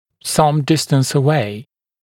[sʌm ‘dɪstəns ə’weɪ][сам ‘дистэнс э’уэй]на некотором удалении